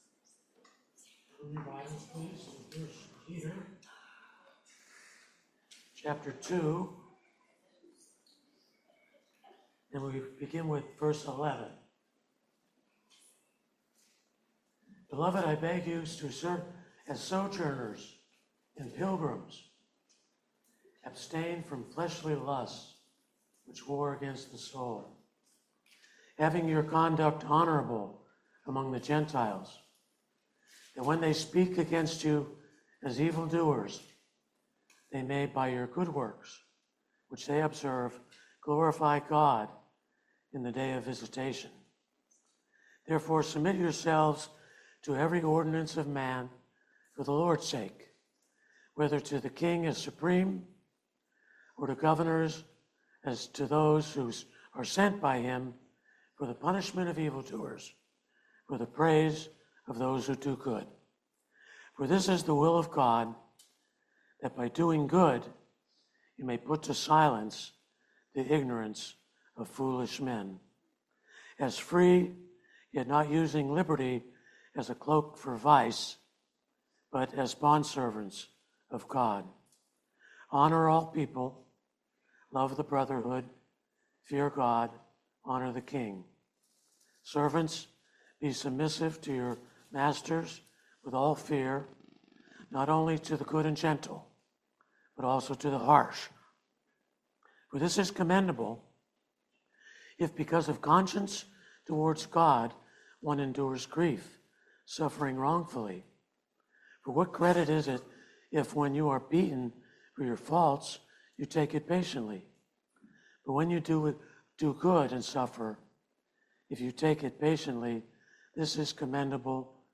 1 Peter 2:11 Service Type: Family Bible Hour Obey God’s Word